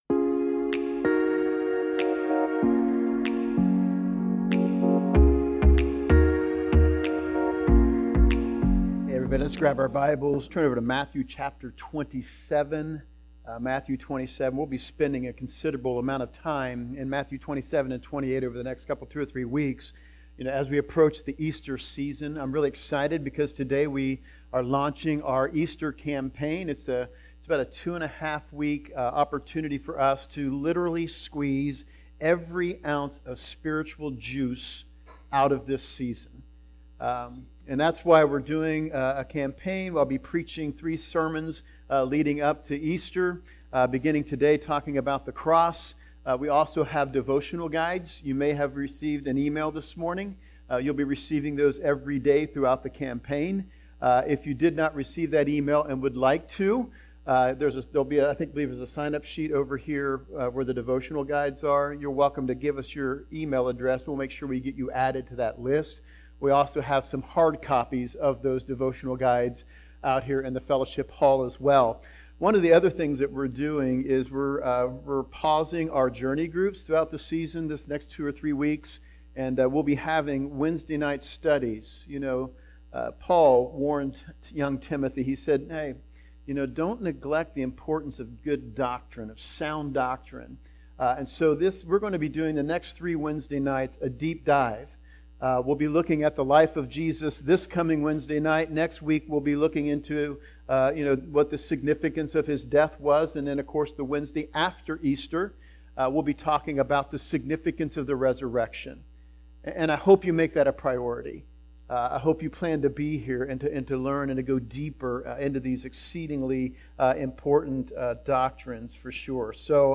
Six Hours One Friday Sermon Series
Intro music